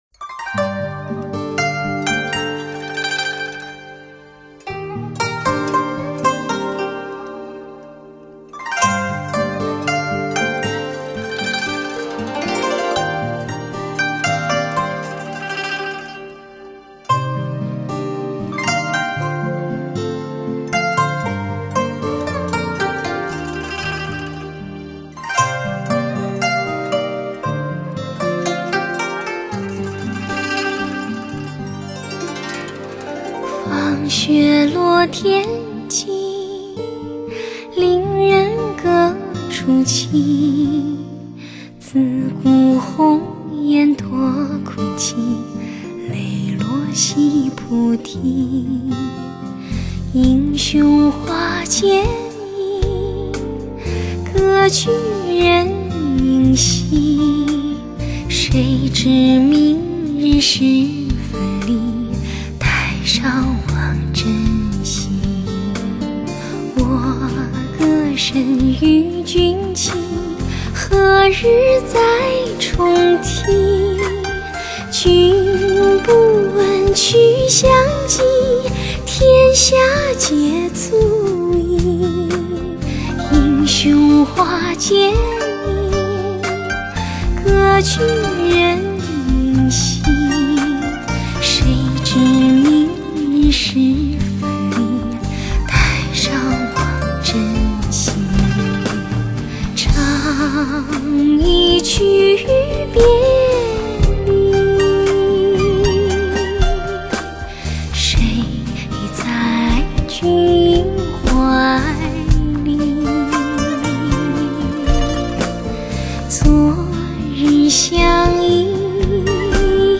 绝对震撼3D精品音效，一次跨国界的声音革命，
甜美 细腻的嗓音 不得不让人喜欢听